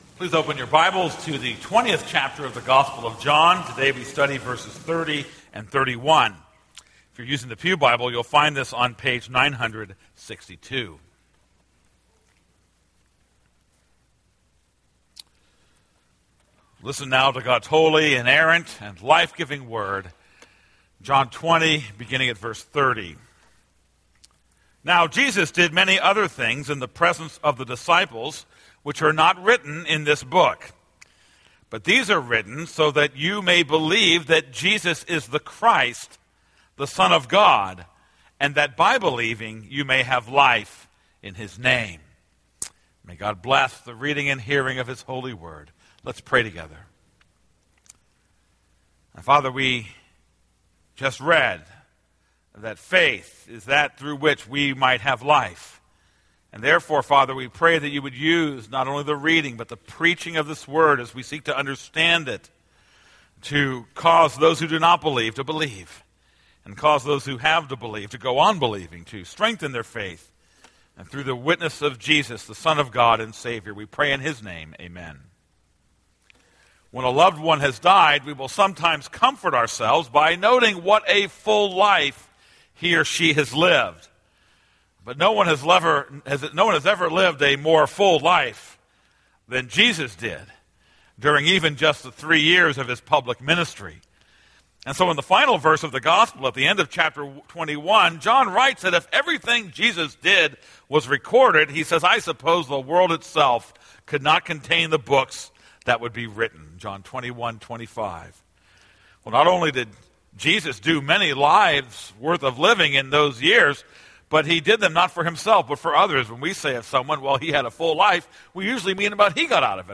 This is a sermon on John 20:30-31.